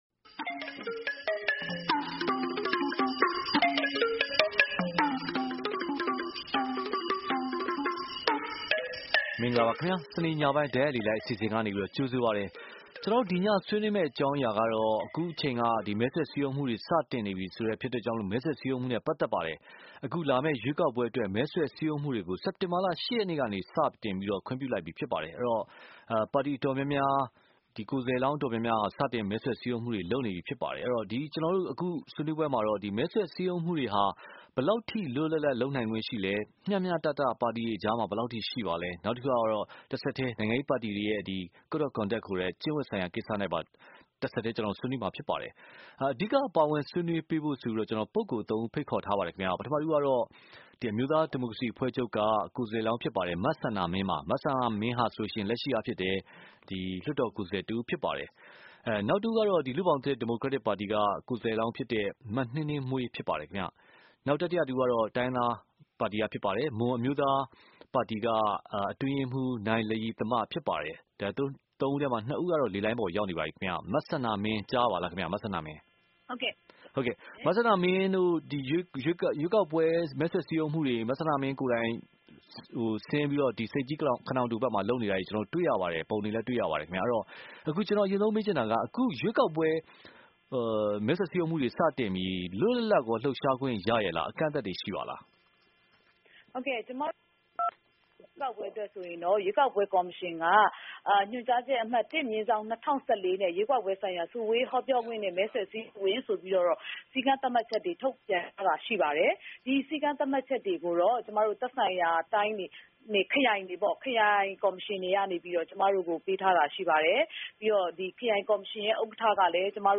မဲဆွယ်စည်းရုံးမှုအတွက် ချထားတဲ့ ပါတီတွေရဲ့ စည်းမျဉ်းစည်းကမ်းတွေနဲ့ မဲဆွယ်စည်းရုံးလှုံဆော်မှုတွေဟာ မျှတလွတ်လပ်မှုရှိရဲ့လားဆိုတာကို ဒီတပတ်စနေနေ့ညတိုက်ရိုက်လေလှိုင်းအစီအစဉ်မှာ ဆွေးနွေးထားပါတယ်။